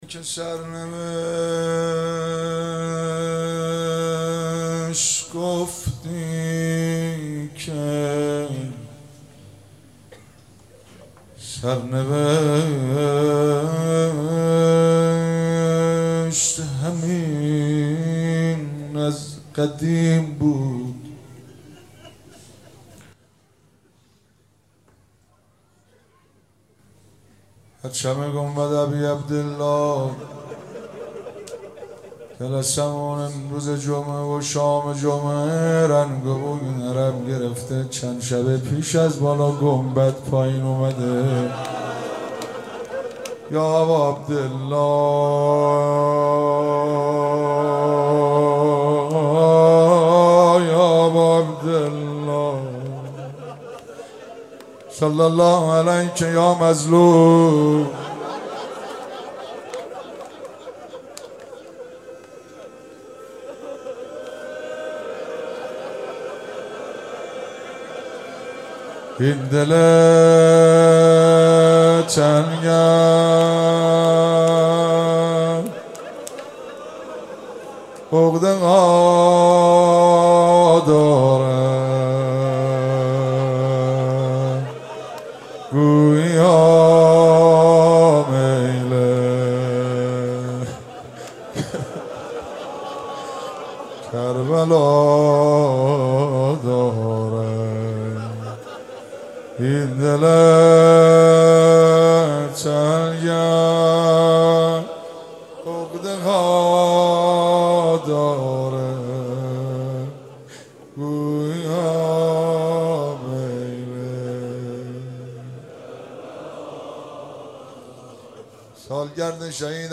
روضه شب ششم